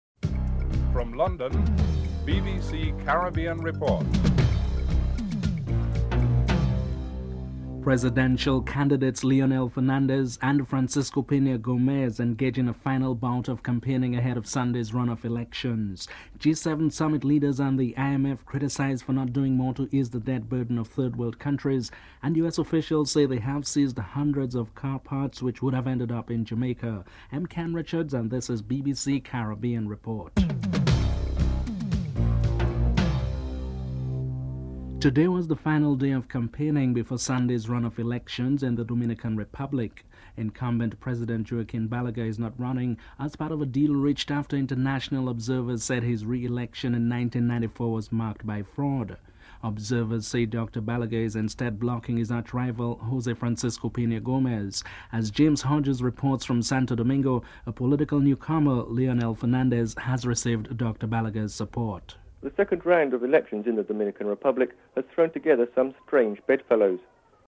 1. Headlines (00:00:34)